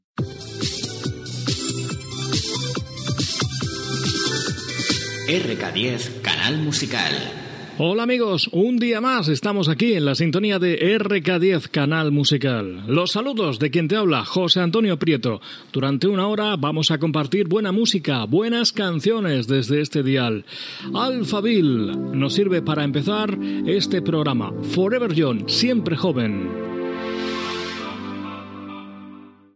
Indicatiu de l'emissora i tema musical
FM